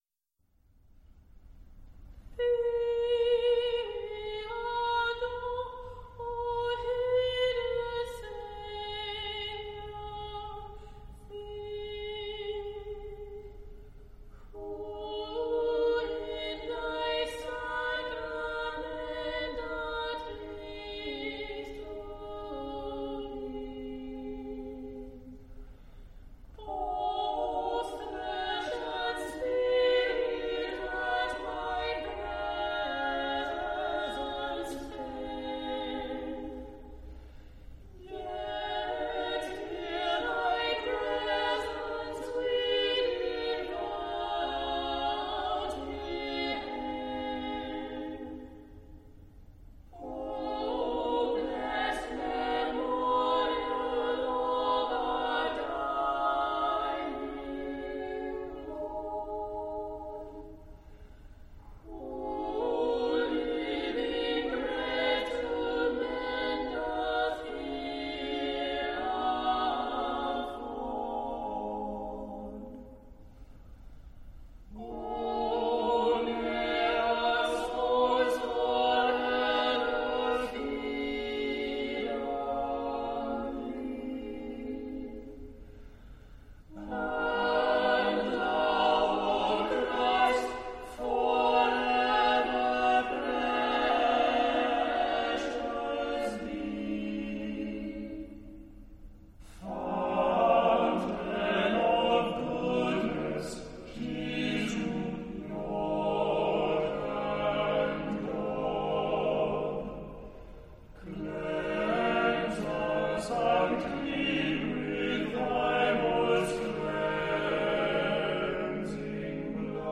Epoque: 20th century  (1990-2000)
Genre-Style-Form: Sacred ; Choir ; Motet
Type of Choir: SSAATTBB  (8 mixed voices )
Tonality: free tonality